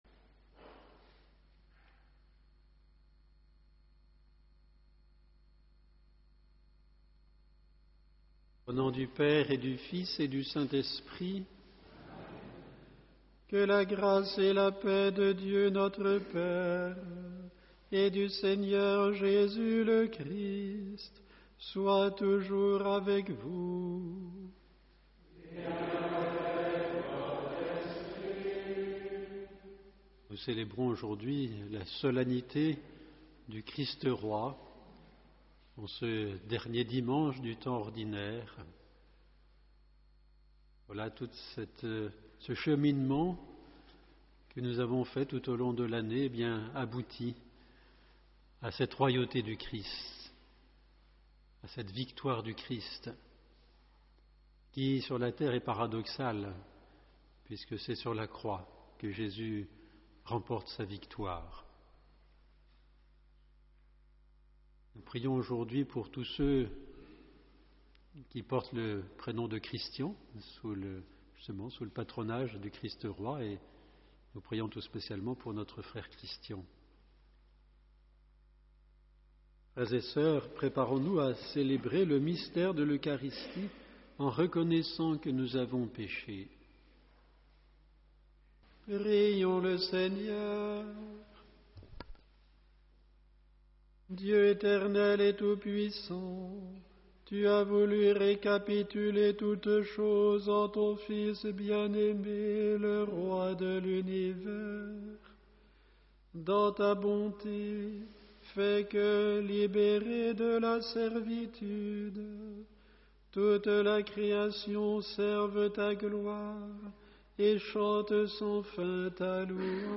Vous pouvez retrouver l’intégralité de la Messe sur Youtube